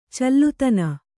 ♪ callutana